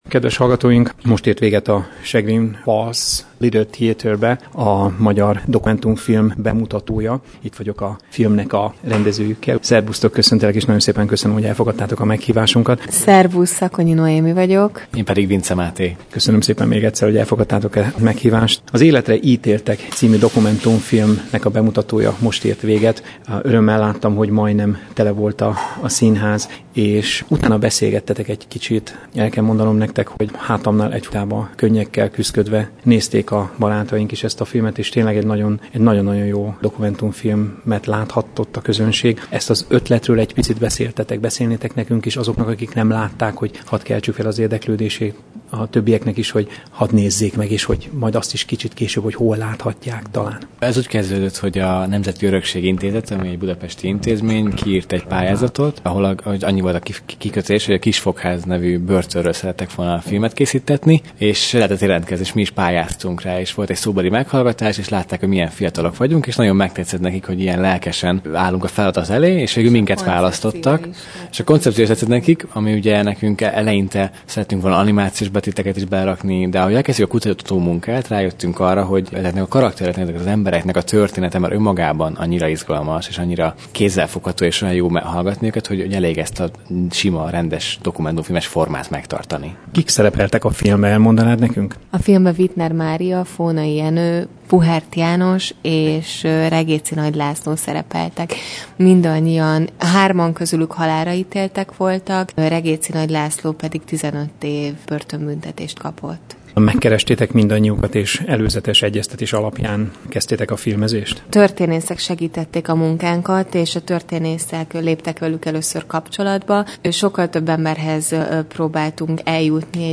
rövid interjúra